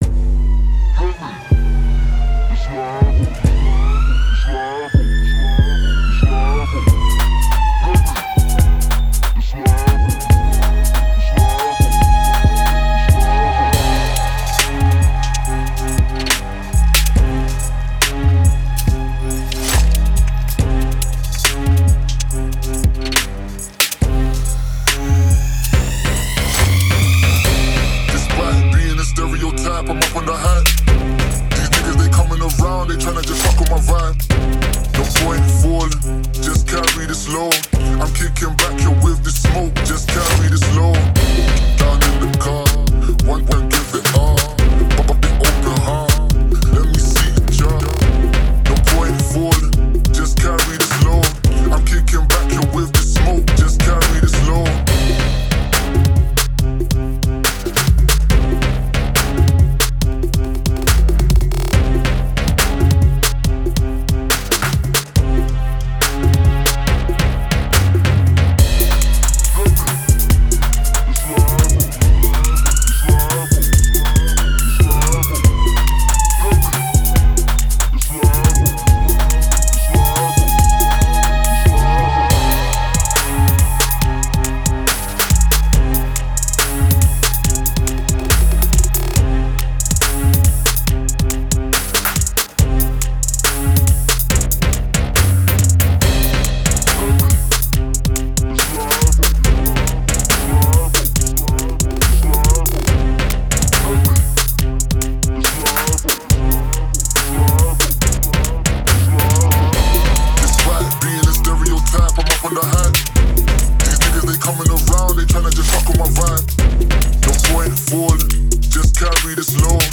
Grime, Trap, Drill, Industrial, Angry, Moody